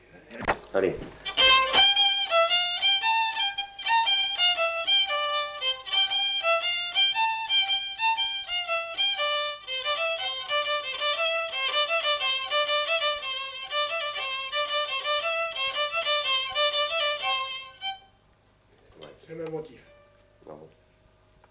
Genre : morceau instrumental
Instrument de musique : violon
Danse : pripet